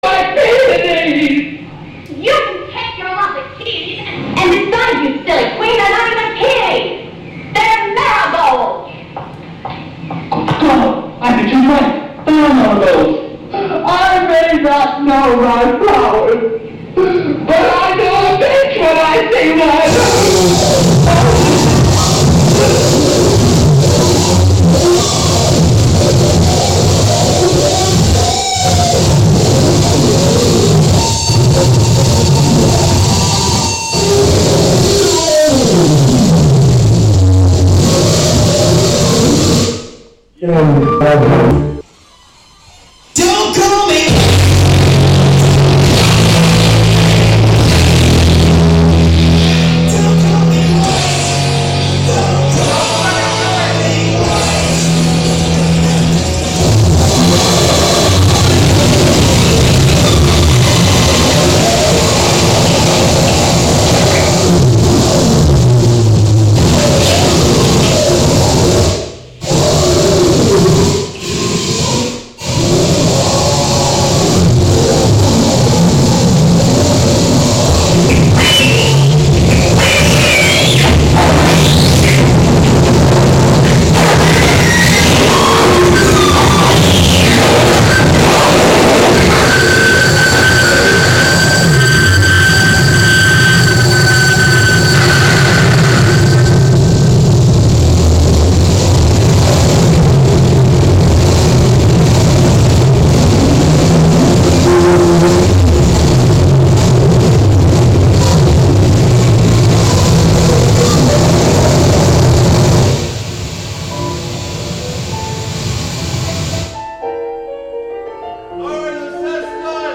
Live from BuddyFest: Buddyfest (Audio)